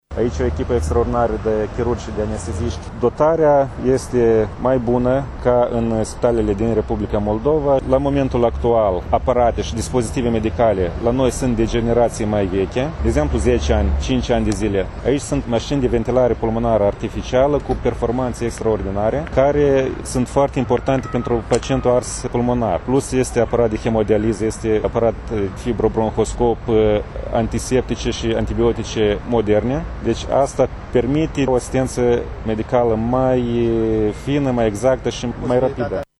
medicul anestezist